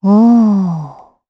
Xerah-Vox_Happy3_jp_b.wav